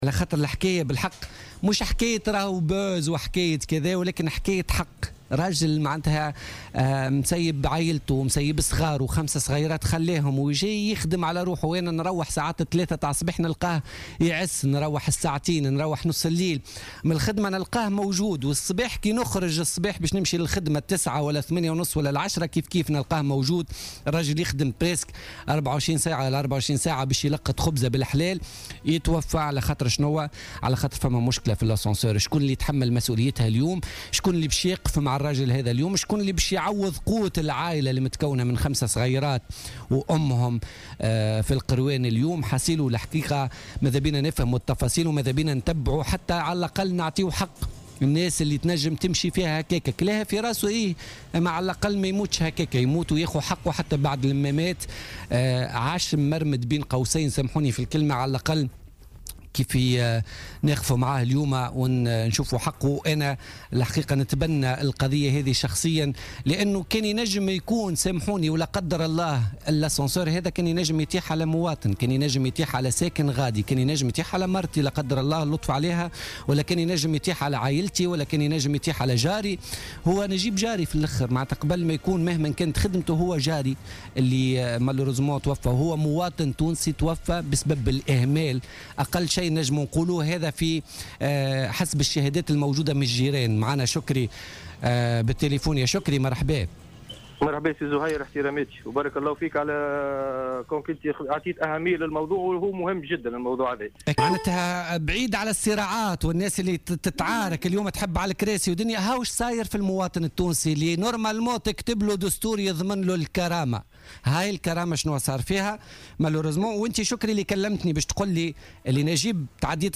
وقد أكد أحد سكان العمارة في مداخلة في بوليتيكا اليوم الثلاثاء 26 جانفي 2016 أن هذه العمارة تفتقد إلى أدنى مقومات السلامة وقد توفي آخرون في هذا المصعد بالذات مؤكدا أن الشروط الموجود في العقد والامتيازات التي ينص عليها ليست موجود على أرض الواقع وفق قوله. وأشار إلى أن المصعد فيه عديد الإشكاليات حيث تقوم الشركة التي قامت بتركيبه منذ 5 سنوات بأشغال اصلاح عليه نظرا لأنه غير مطابق لشروط السلامة.